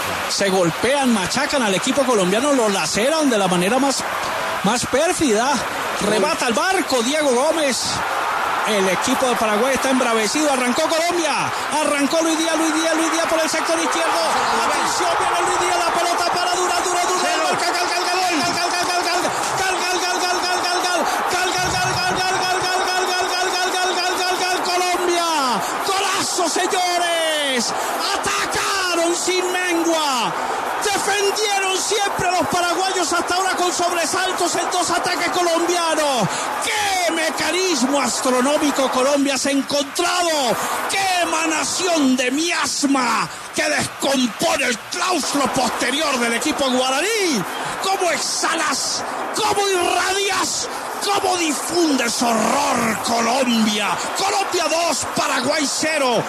Martín De Francisco no pudo contener su emoción con el golazo de Jhon Jader Durán en Eliminatorias.
El periodista deportivo Martín De Francisco narró con su particular emoción el segundo gol de la Selección Colombia ante Paraguay por las Eliminatorias, tanto convertido por el delantero del Al-Nassr, Jhon Jader Durán.